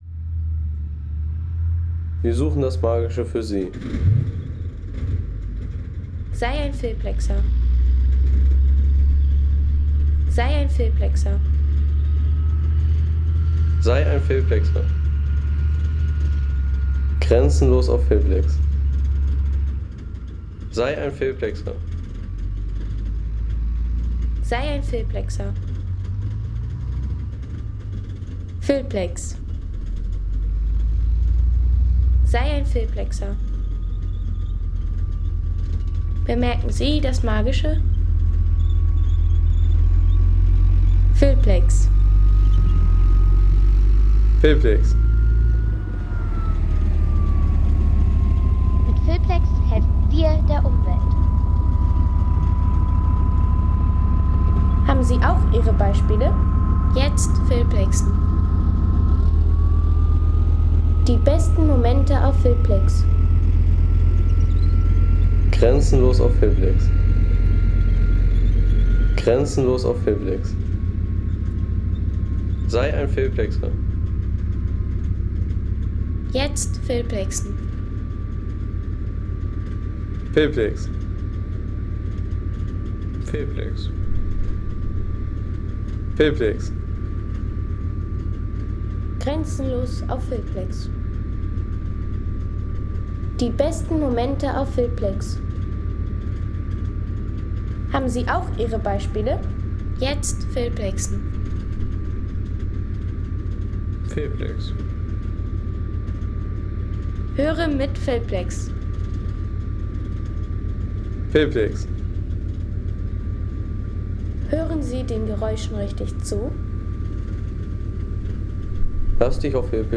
Leopard 1A3 und T55-T: Panzer auf dem Feld